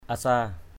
/a-sa:/